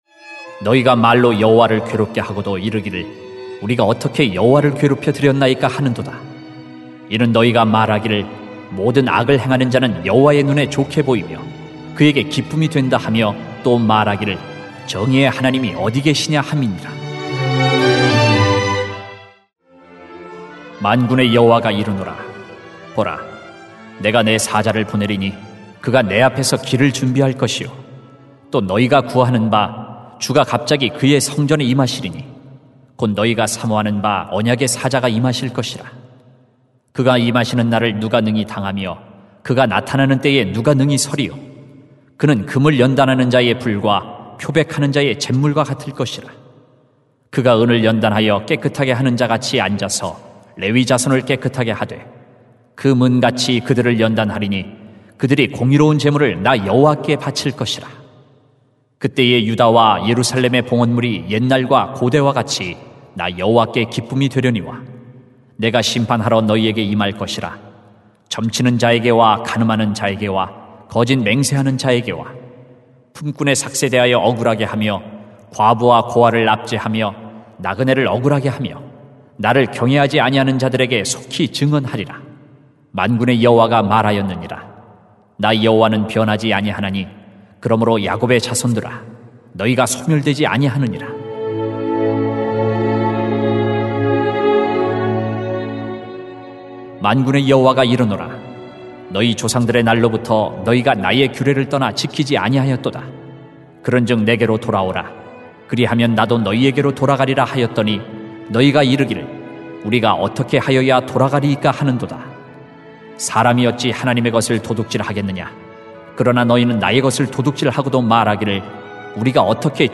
[말 2:17-3:12] 하나님을 괴롭히지 마세요 > 새벽기도회 | 전주제자교회